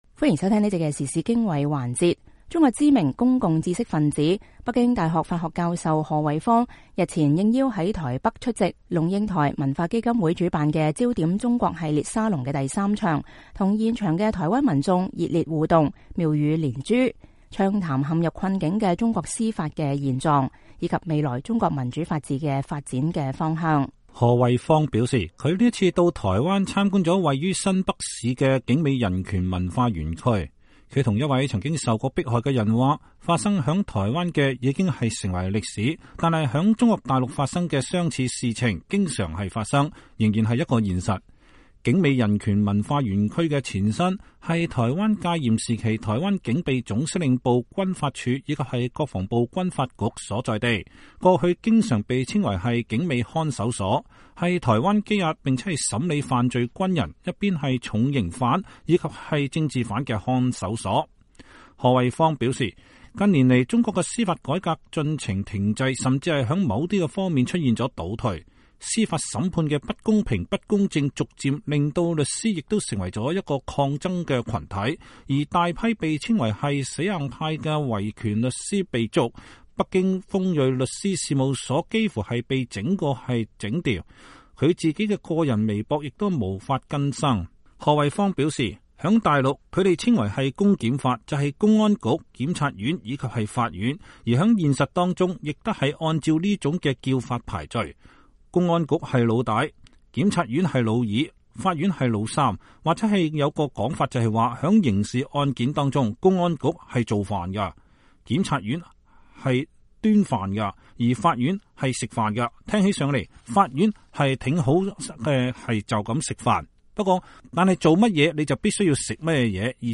中國知名公共知識分子、北京大學法學教授賀衛方日前應邀在台北出席“龍應台文化基金會”主辦的《焦點中國》系列沙龍的第三場，與現場的台灣民眾熱烈互動，妙語連珠，暢談陷入困境的中國司法現狀，以及未來中國民主法治的發展方向。